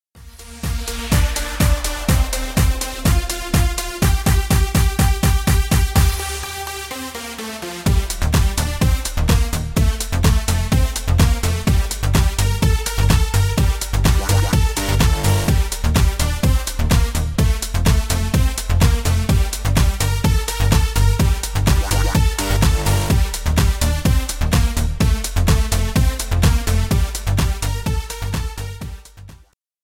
Электроника
клубные # без слов